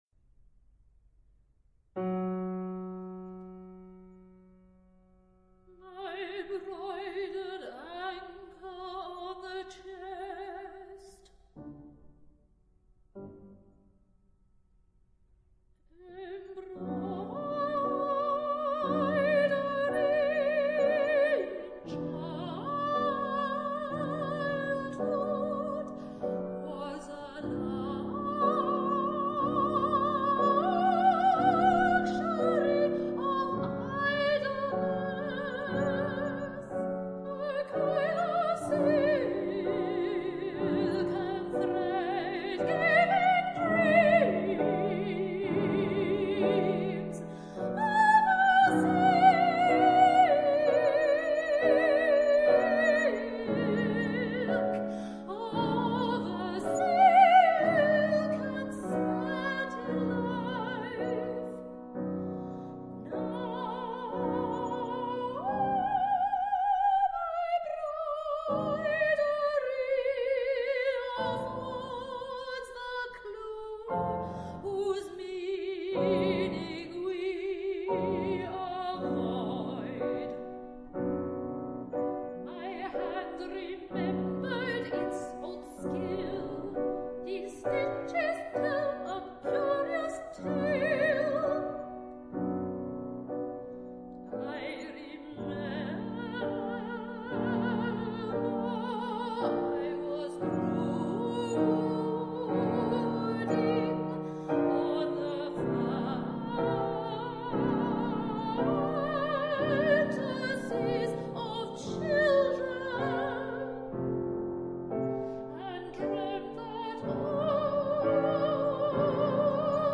Tracks 5 and 6 recorded at the Wigmore Hall, 26 August 2009